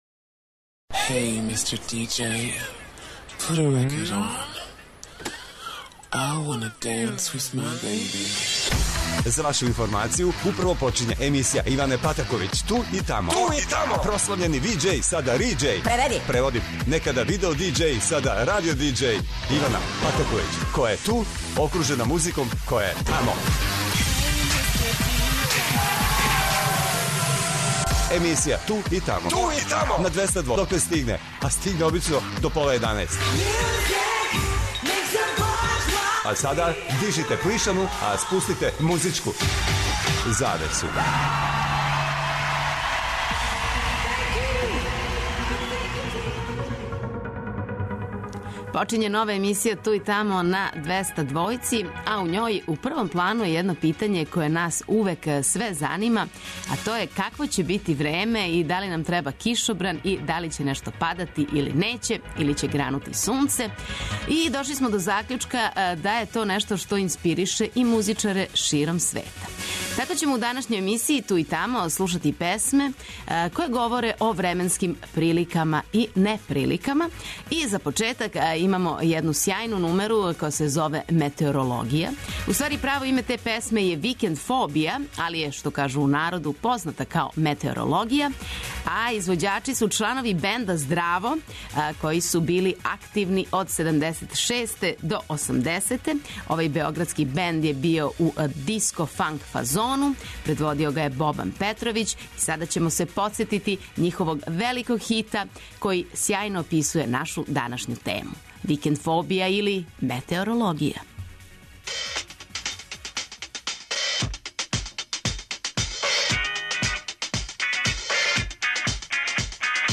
У новом издању емисије Ту и тамо још једна распевана тема - временске прилике и (не)прилике. Слушаћемо домаће и стране хитове који у насловима доносе ветар, кишу, маглу, биће и сунца, облака и олуја са грмљавином. Наравно, као и увек и слушаоци могу да се укључе и дају своје предлоге.